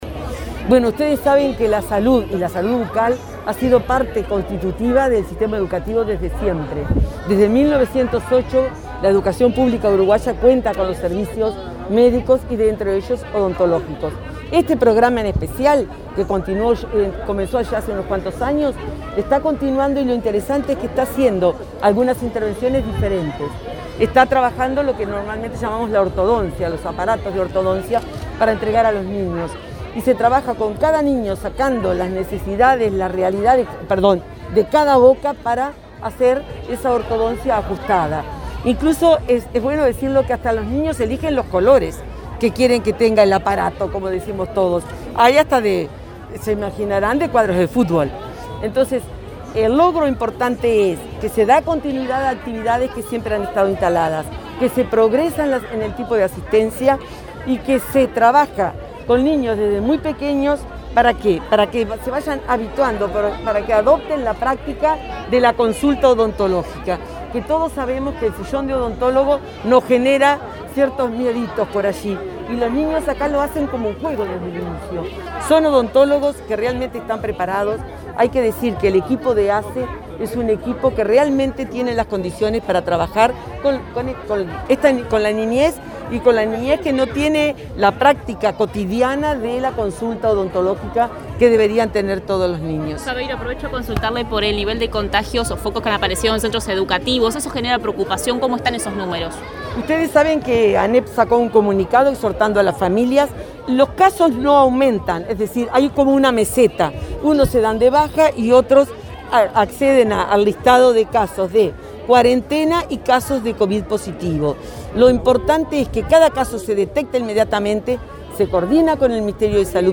Declaraciones a la prensa de la directora de Primaria, Graciela Fabeyro